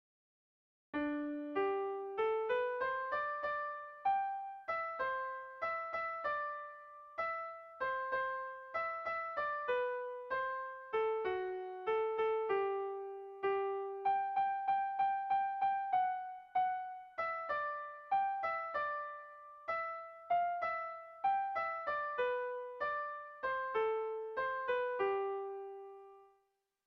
Irrizkoa
ABDE